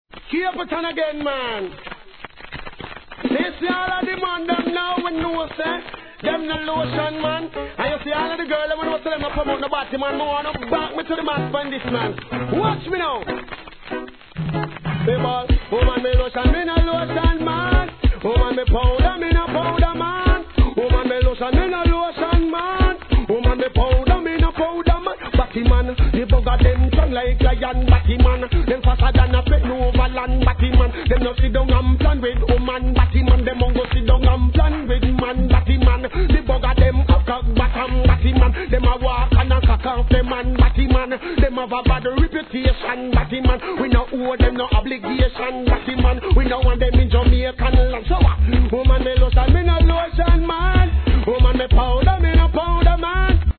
イントロのノイズが目立ちます
REGGAE